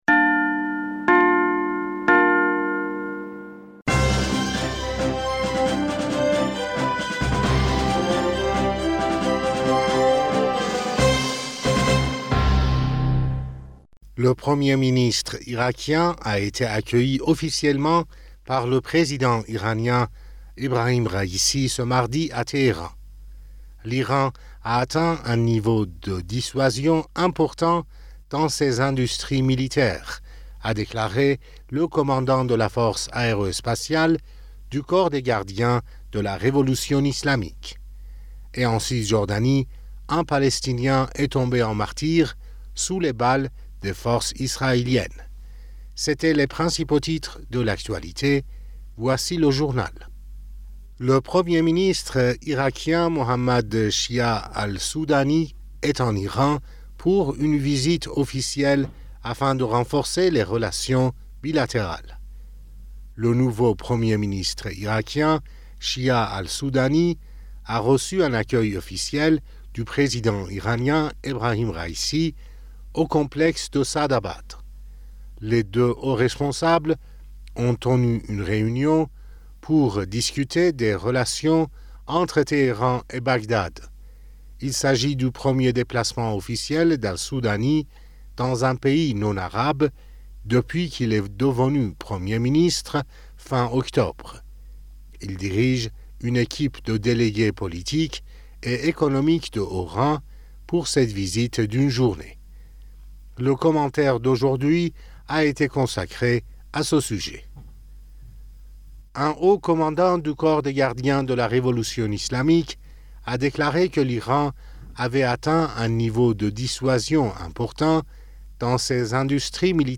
Bulletin d'information du 29 Novembre